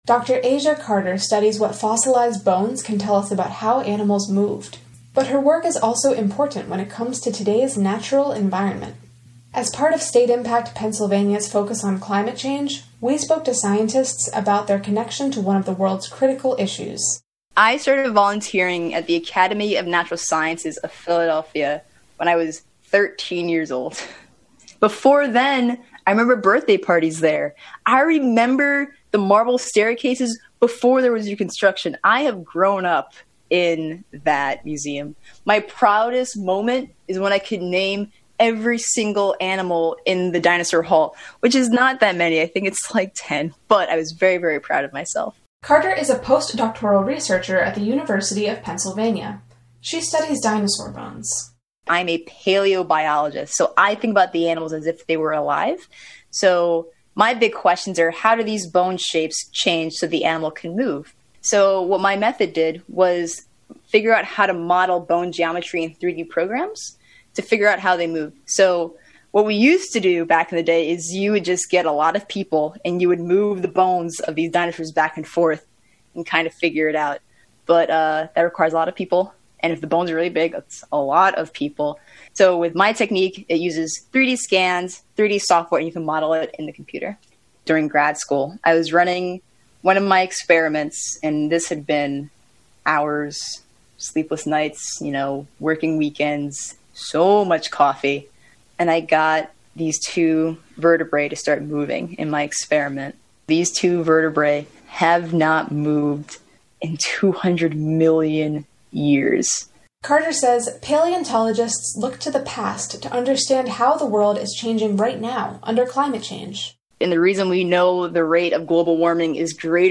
This week, with a series of three audio stories as part of StateImpact Pennsylvania’s participation in the Covering Climate Now initiative, we’re taking time to talk about dinosaurs, fish and wetlands.